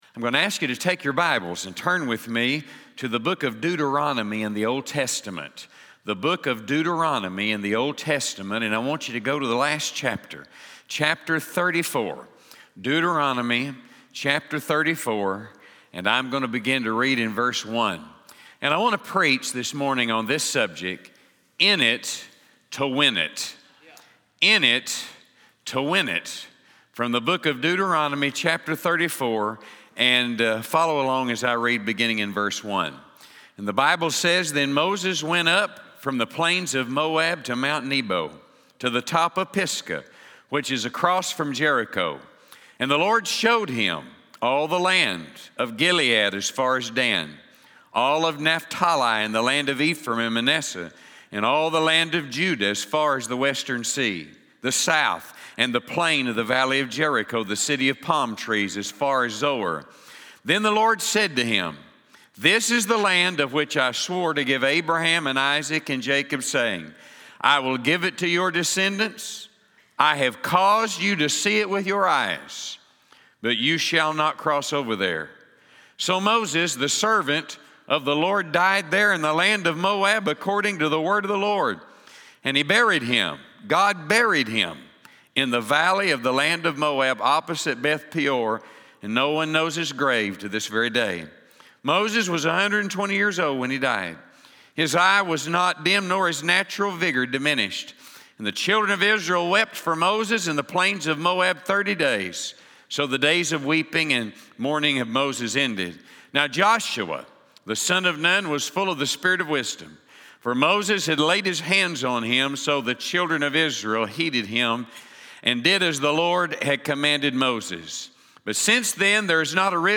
From the morning session of the Real Momentum Conference on Saturday, August 3, 2019